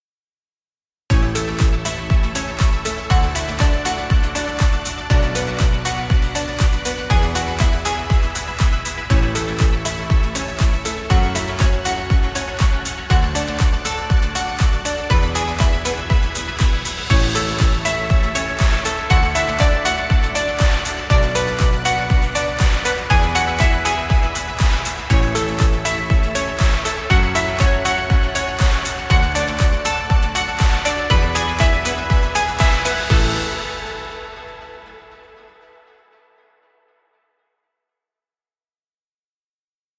Inspirational happy music.